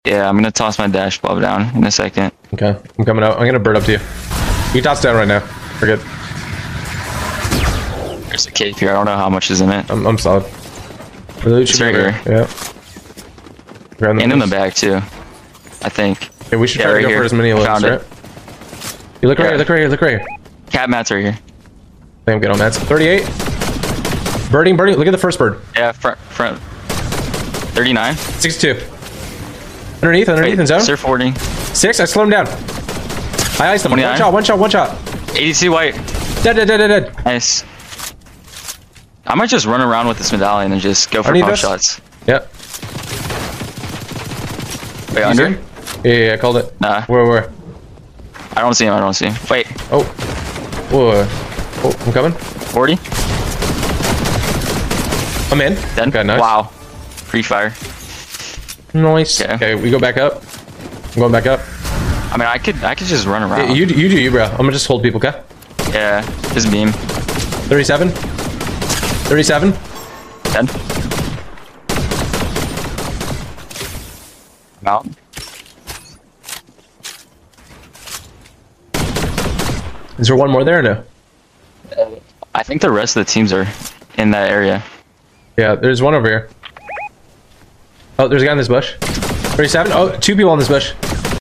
New Gun in Fortnite USA sound effects free download